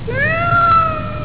Cat 1
CAT_1.wav